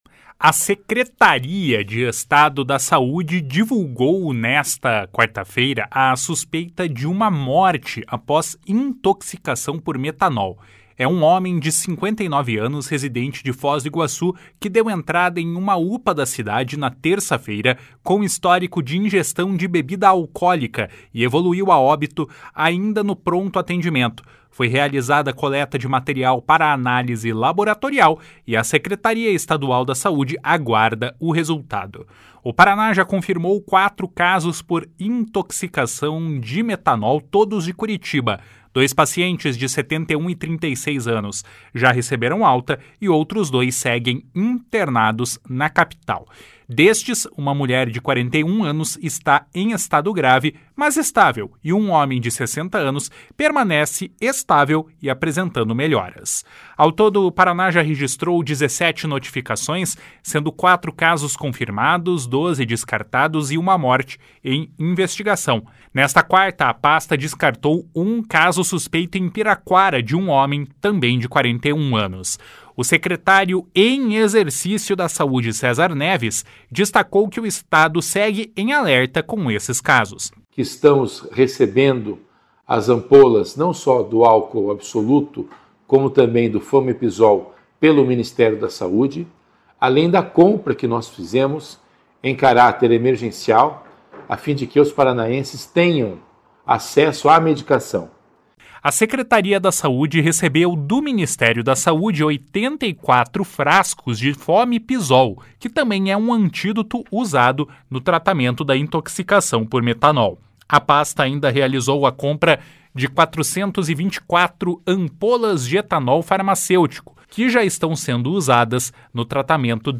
O secretário em exercício da Saúde, César Neves, destacou que o Estado segue em alerta com os casos. // SONORA CÉSAR NEVES //